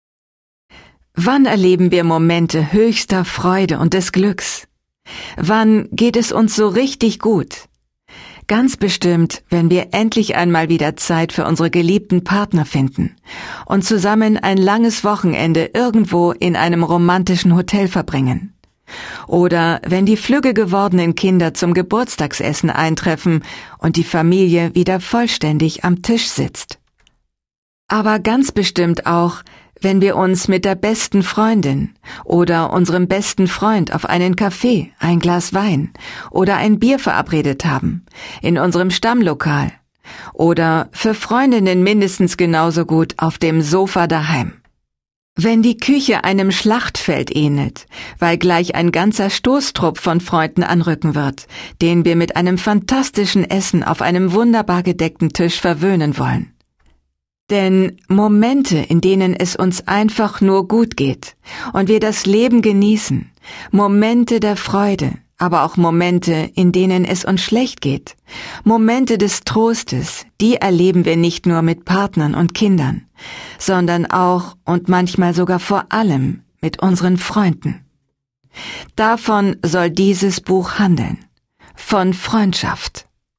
Inzwischen bin ich Sprecherin aus Leidenschaft und meine tiefe Motivation ist es, die Texte mit Hingabe, Authentizität und Emotionalität einzusprechen und angenehm lebendig werden zu lassen.